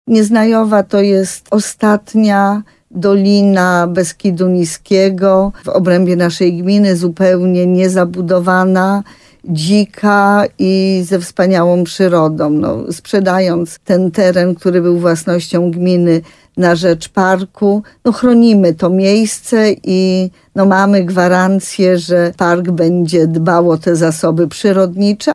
– mówi wójt gminy Sękowa, Małgorzata Małuch.